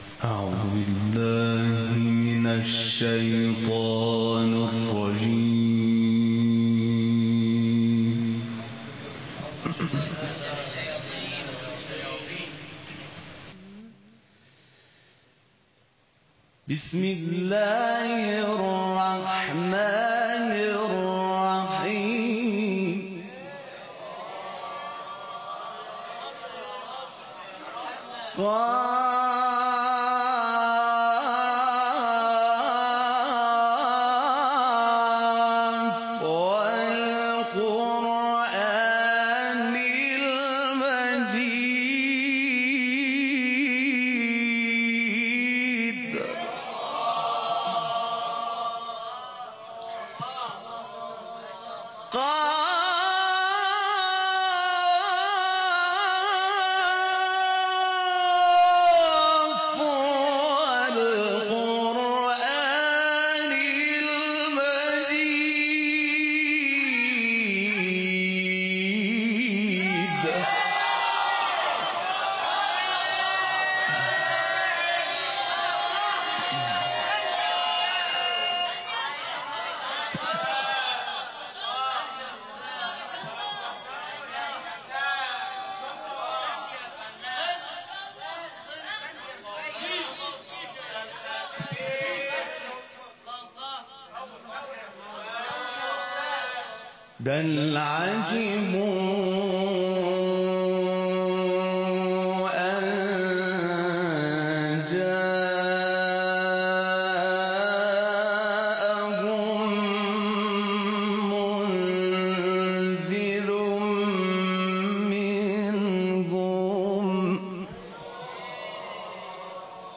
Recitation of Verses from Surah Qaaf